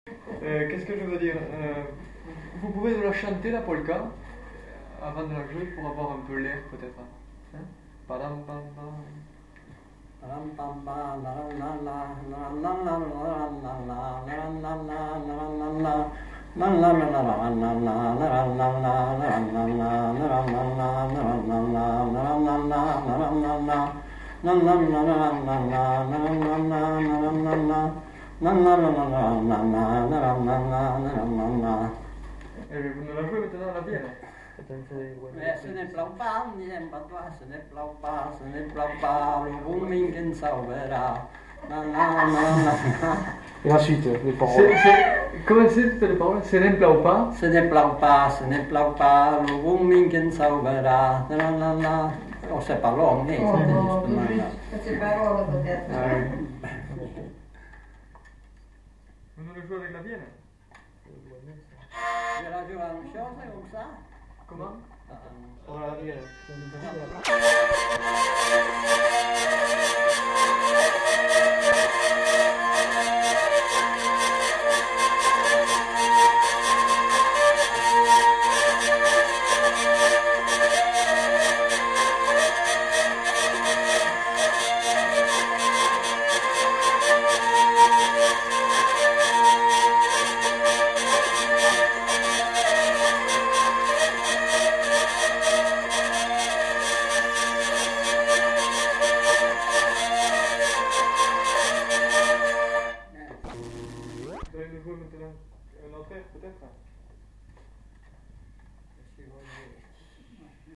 Aire culturelle : Petites-Landes
Lieu : Lencouacq
Genre : morceau instrumental
Instrument de musique : vielle à roue
Danse : polka
Notes consultables : La mélodie est d'abord fredonnée avec quelques paroles données puis elle est jouée à la vielle à roue.